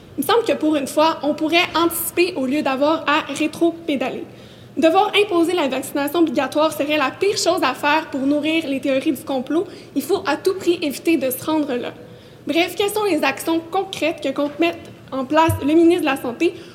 L’élue indépendante  de la circonscription Marie-Victorin, à Longueuil, a fait connaître son inquiétude lors de la période de question du 3 décembre à l’Assemblée nationale.
Extrait Catherine Fournier